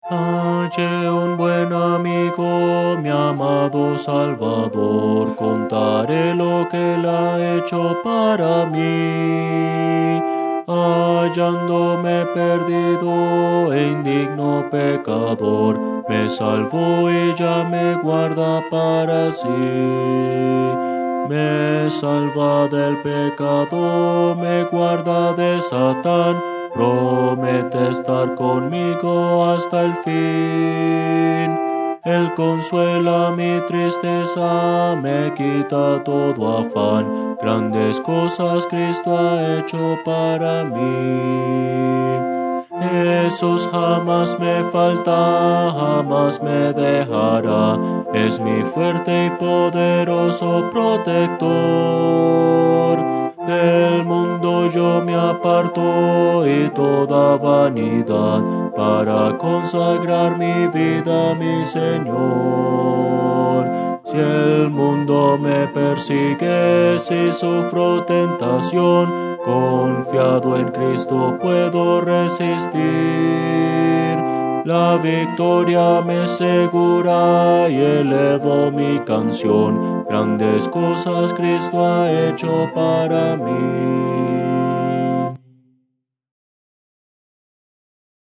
Voces para coro
Contralto – Descargar